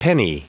Prononciation du mot penny en anglais (fichier audio)
penny.wav